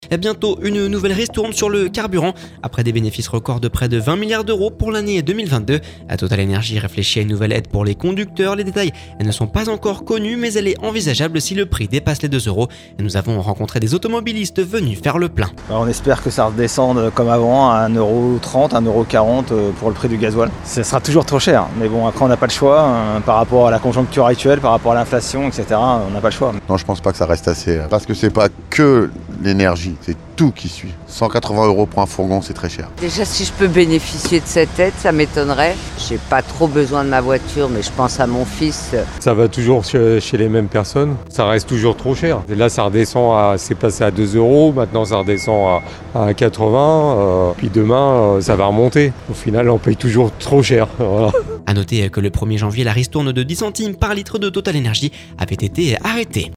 Après des bénéfices record de près de 20 milliards d'euros pour l’année 2022, TotalEnergie réfléchit à une nouvelle aide pour les conducteurs. Les détails de ne sont pas encore connus mais elle est envisageable si le prix dépasse les 2€. Nous avons rencontré des automobilistes venu faire le plein…